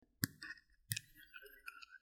На этой странице собраны различные звуки скрежета зубами – от непроизвольного бруксизма до намеренного скрипа.
Звук бруксизму